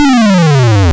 BowserFall.wav